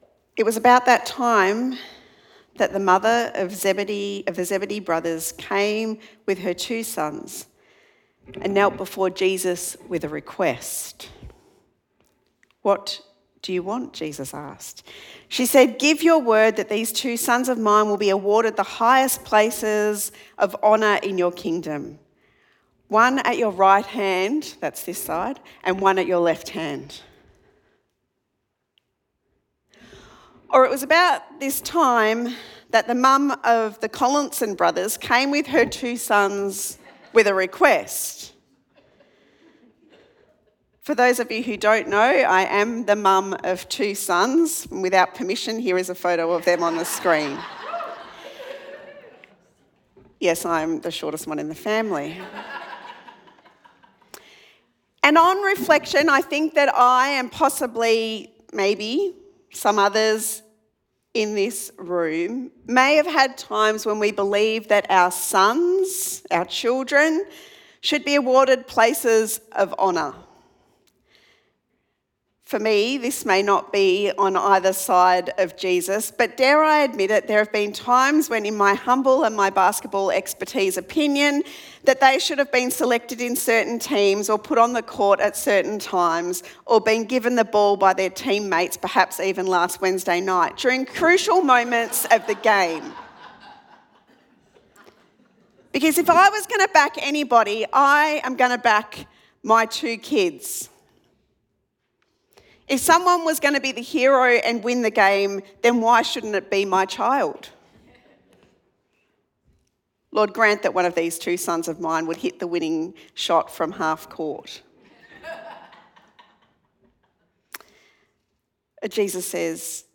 Sermon Podcasts Wisdom in the Desert